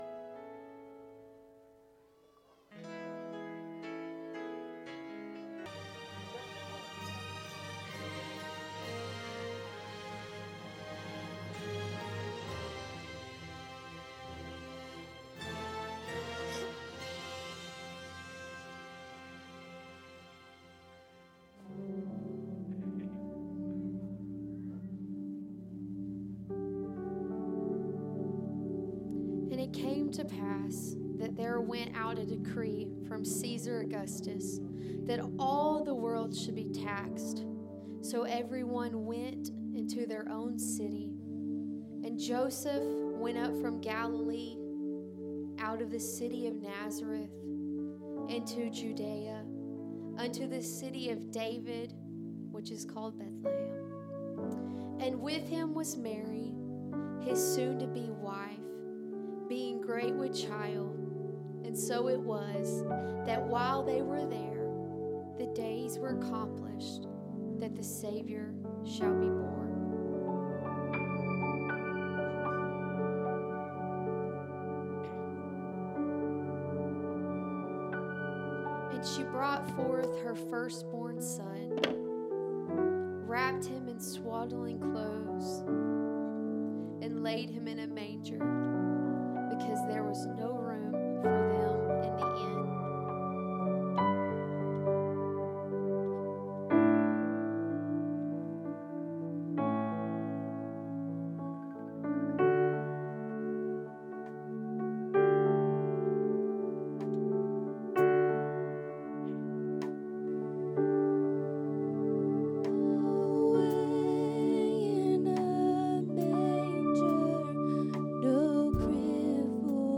1 Christmas Service 1:08:12